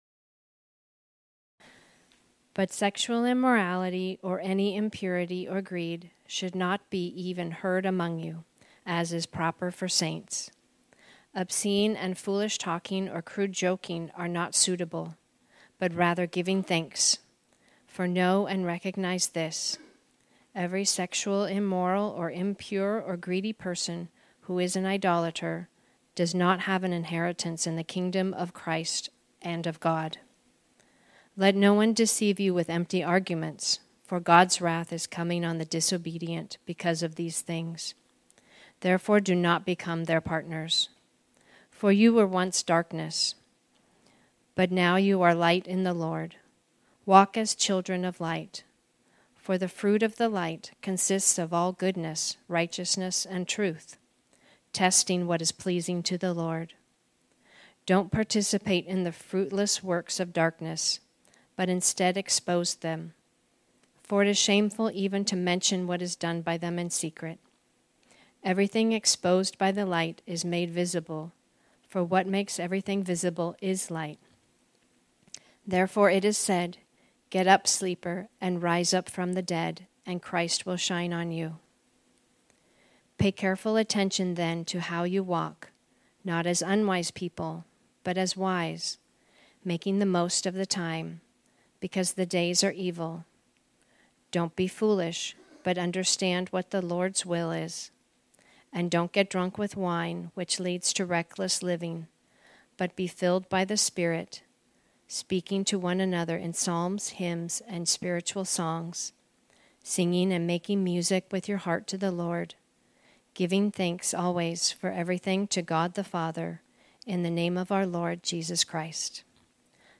This sermon was originally preached on Sunday, November 12, 2023.